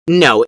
Sound Effects
Test Tube Says No Bass Boosted